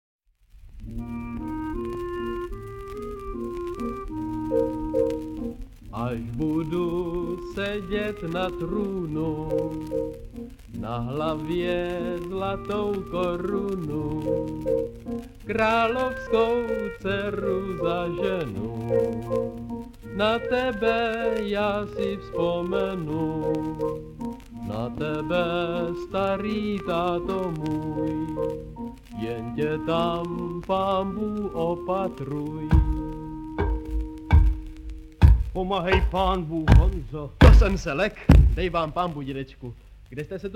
Historická nahrávka pohádky.